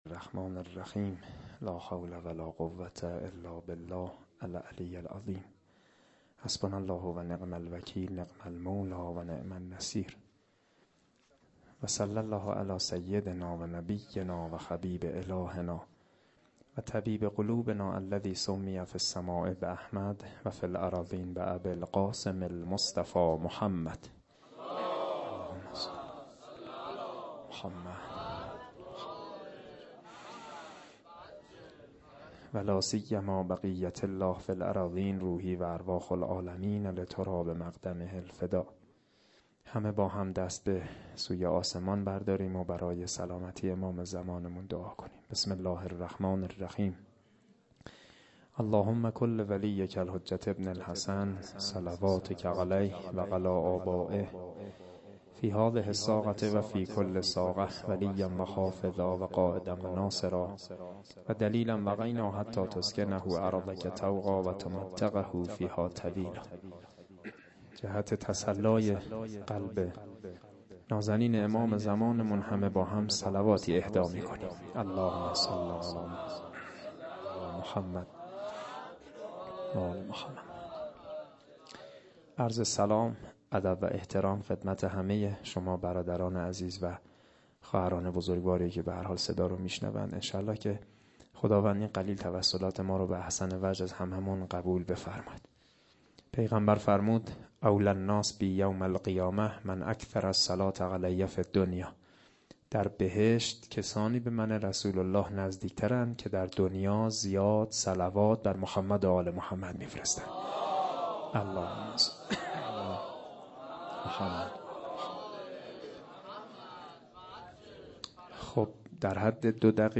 01asheghan-sokhanrani5.lite.mp3